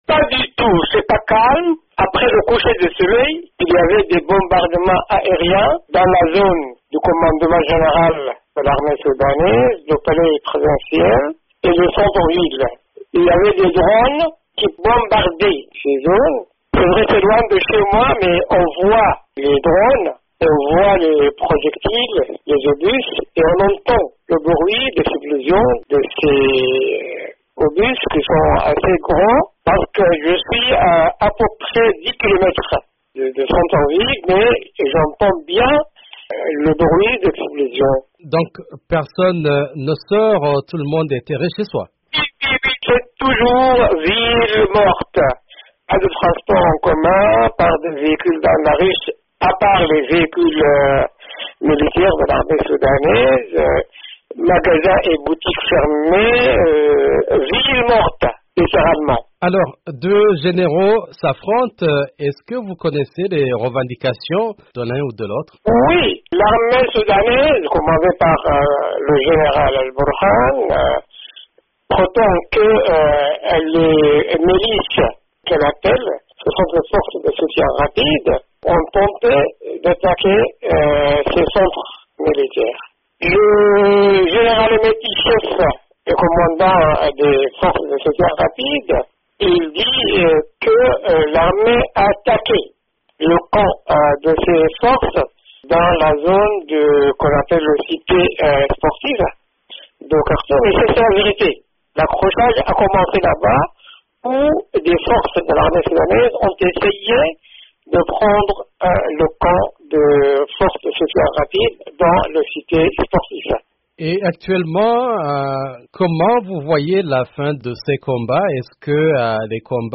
"Des drones qui bombardent": témoignage d'un journaliste à Khartoum